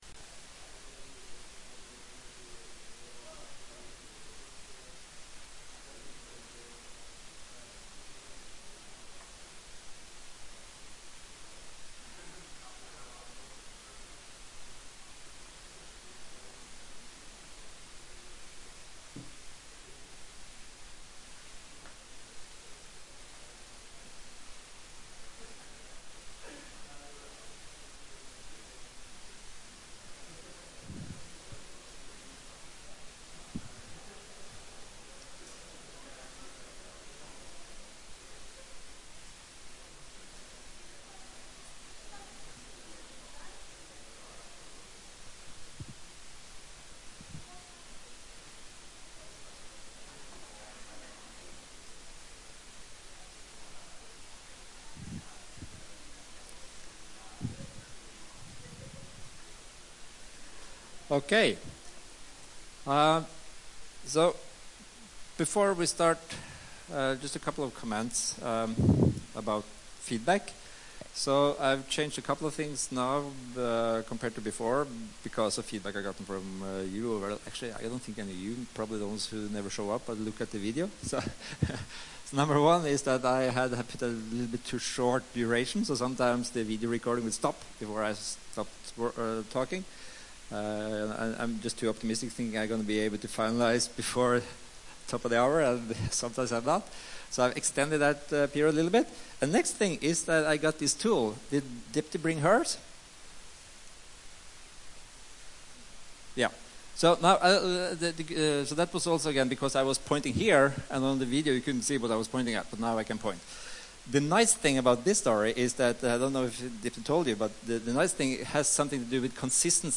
EER modelling - part 2 - NTNU Forelesninger på nett
Rom: Store Eureka, 2/3 Eureka